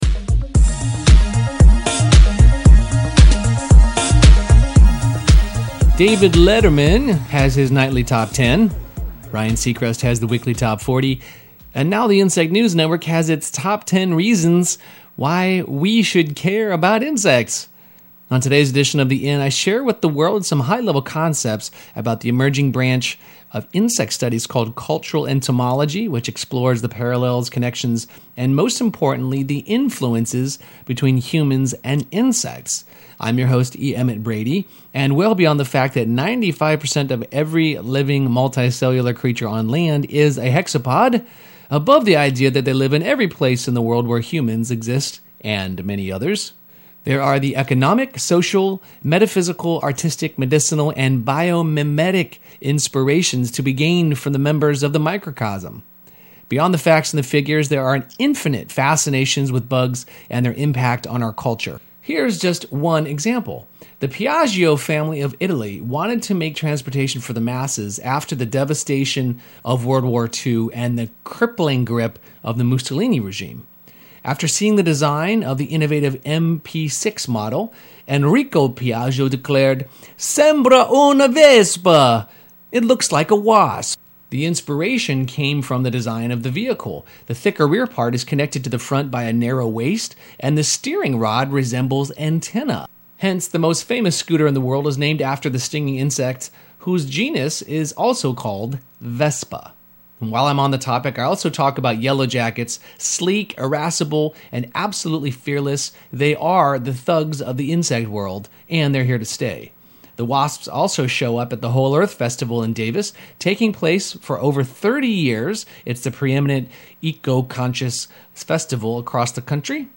And Now the Insect News Network has its Top 10 reason why we should care about insects. I also play some segments of “In your Words” from the Whole Earth Festival in Davis, CA.